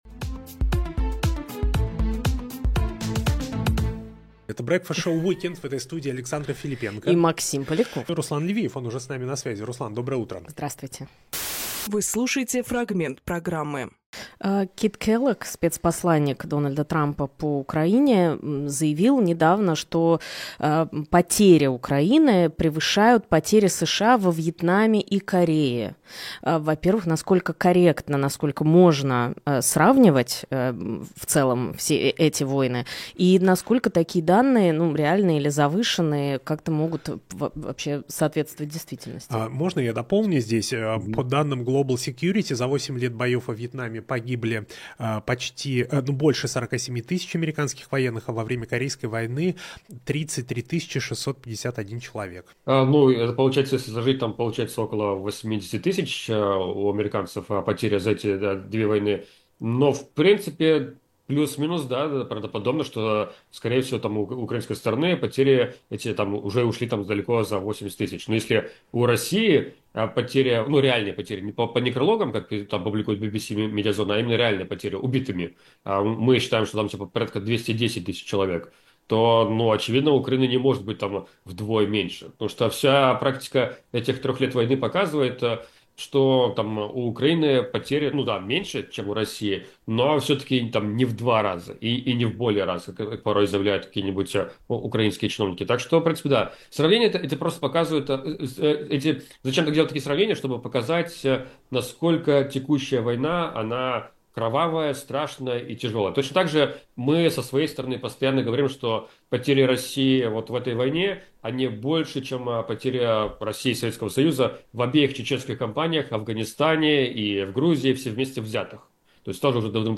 Фрагмент эфира от 26 января.